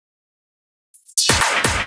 Fill 128 BPM (38).wav